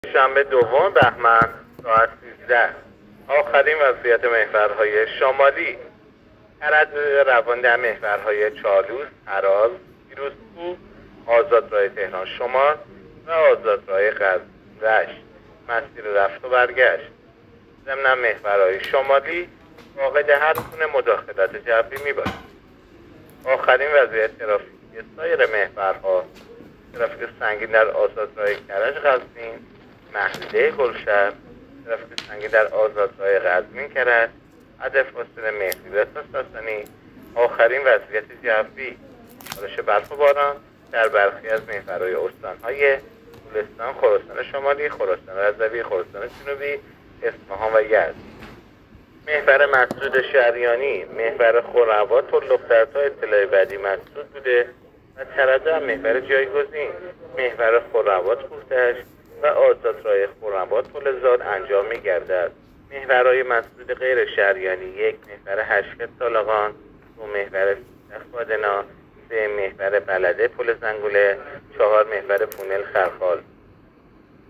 گزارش رادیو اینترنتی از آخرین وضعیت ترافیکی جاده‌ها ساعت ۱۳ دوم بهمن؛